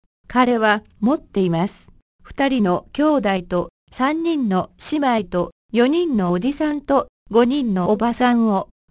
◇音声は日本語、英語ともに高音質のスピーチエンジンを組み込んだ音声ソフトを使って編集してあります。
音声−問題